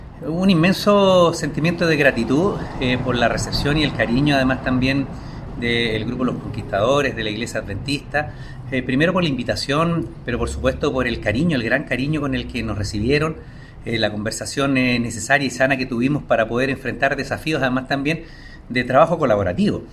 04-ALCALDE-Oscar-Calderon.mp3